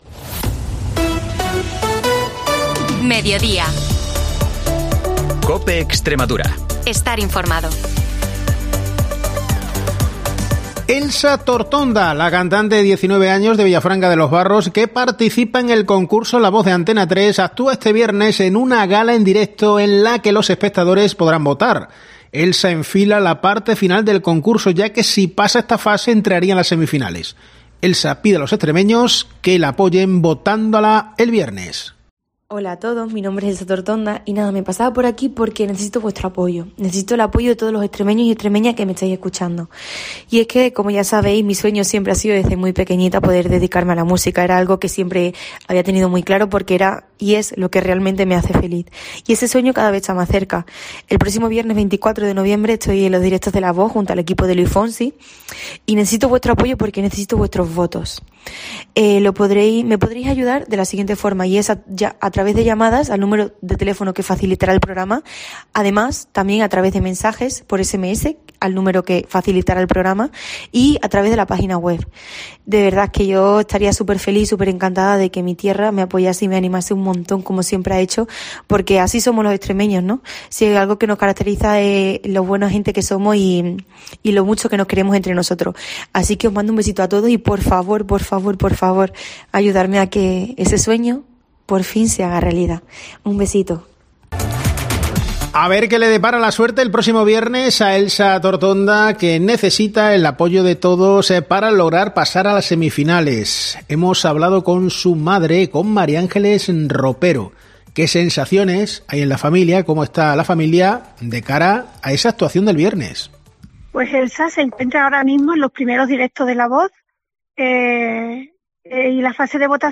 Información y entrevistas